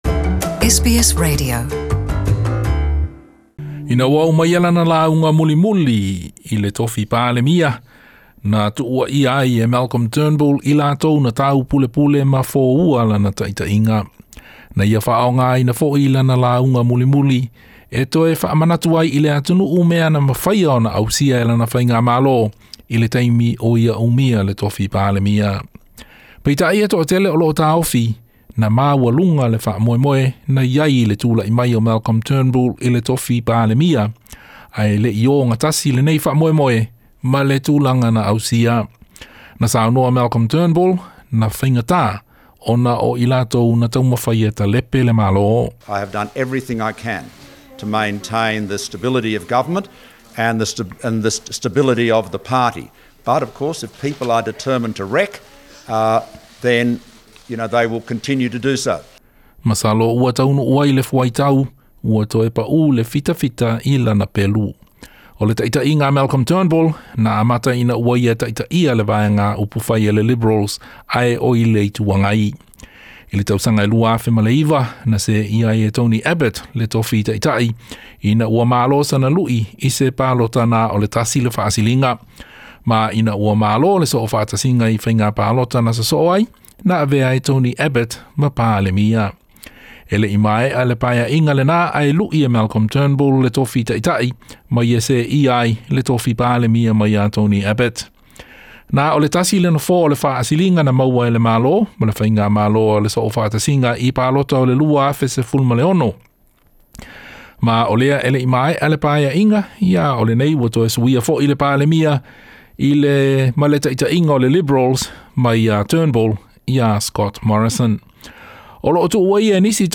Lauga faamavae a Malcolm Turnbull
Malcolm Turnbull i lana saunoaga mulimuli i le tofi palemia.